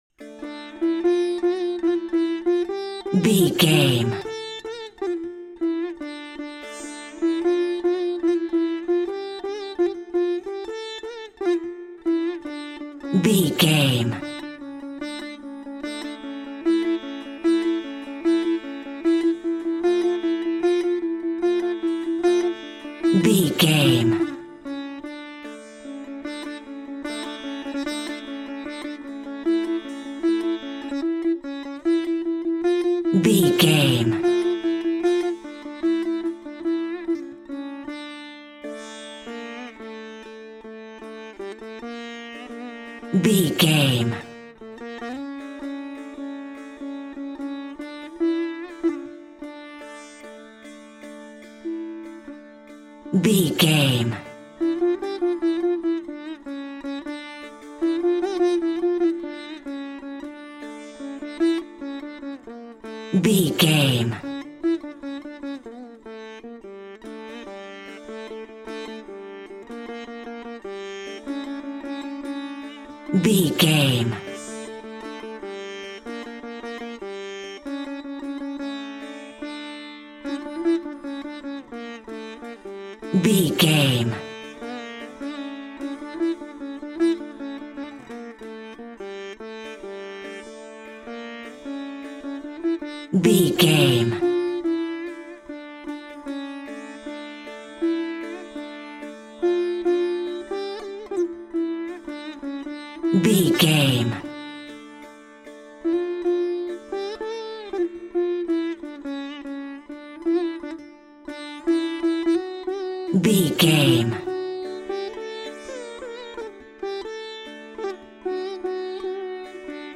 Aeolian/Minor
World Music